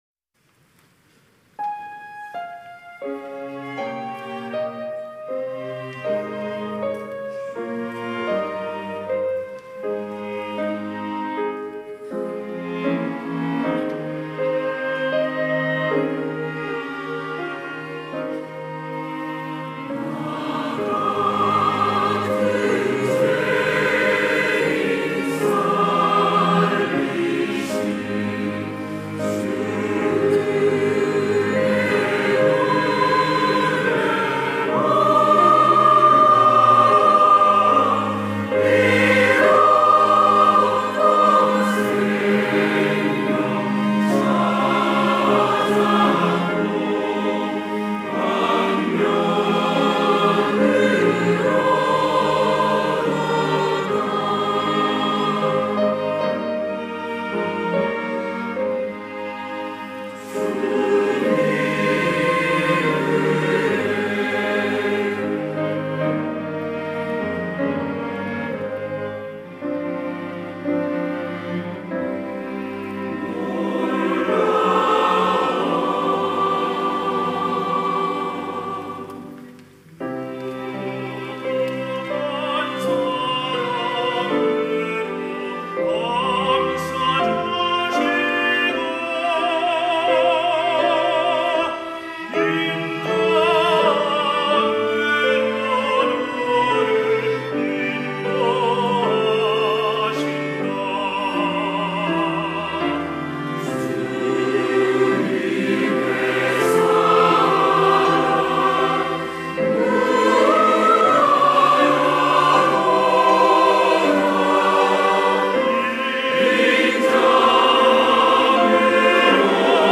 호산나(주일3부) - 주 은혜 놀라와
찬양대